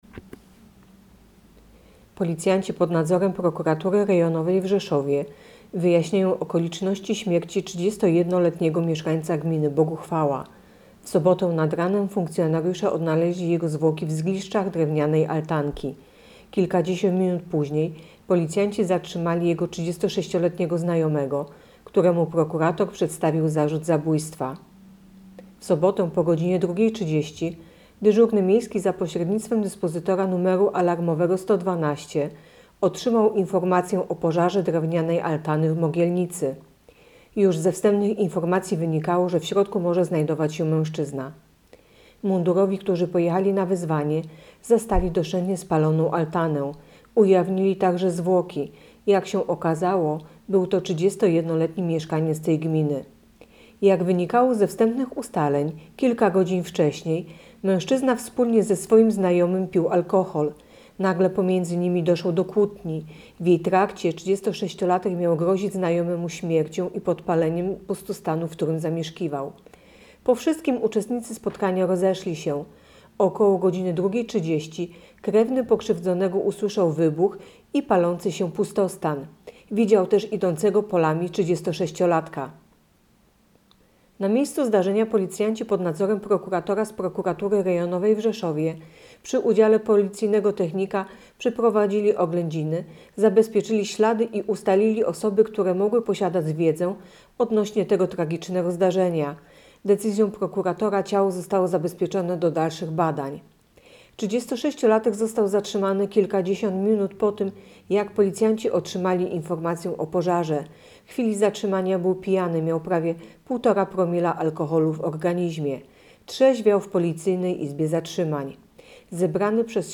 Opis nagrania: Nagranie informacji pt.